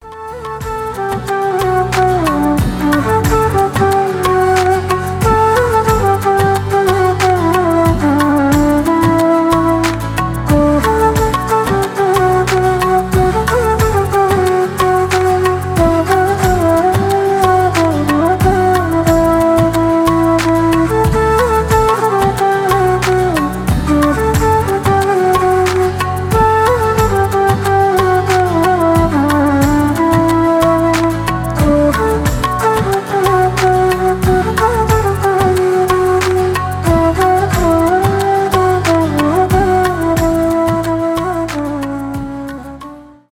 на флейте , инструментальные
индийские , без слов , романтические